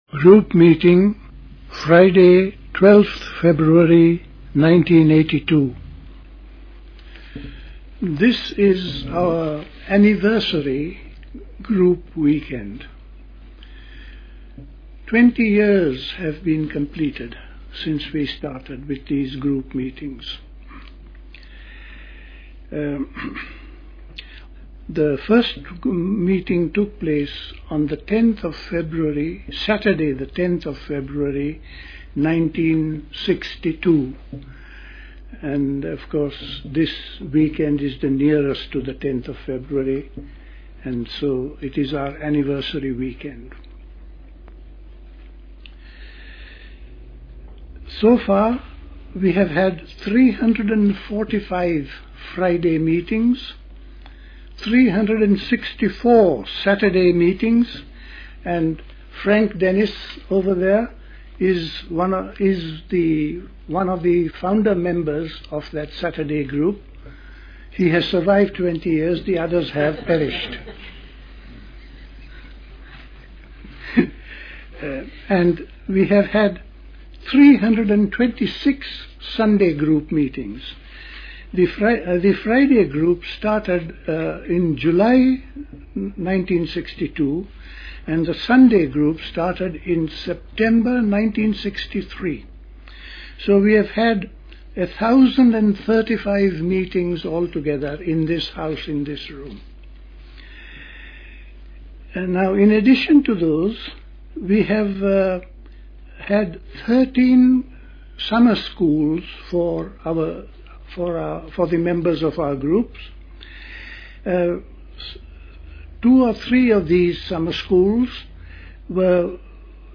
The 20th anniversary weekend of talks. Our concern has been the living of the religious life.